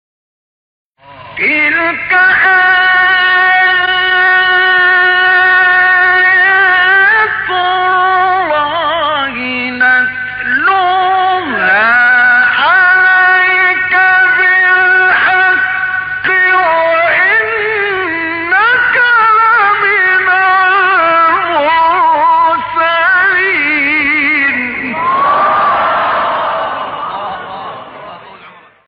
مقطع عشاق مصری استاد مصطفی اسماعیل | نغمات قرآن | دانلود تلاوت قرآن